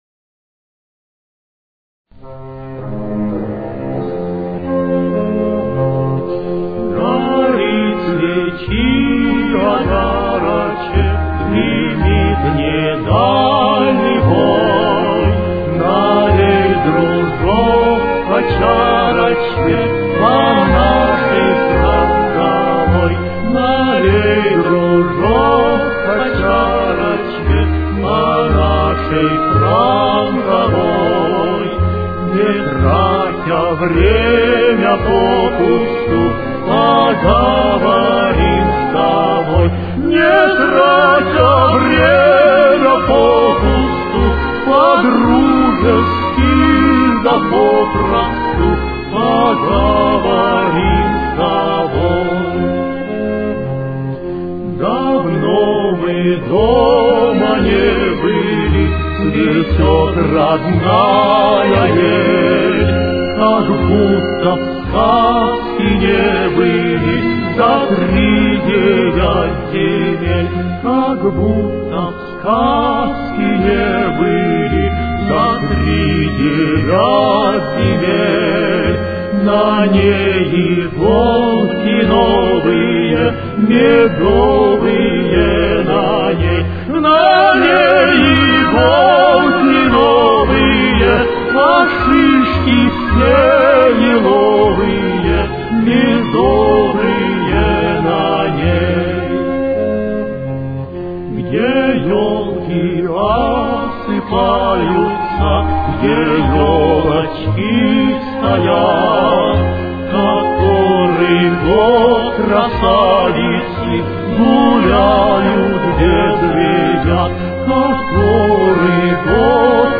Фа минор. Темп: 55.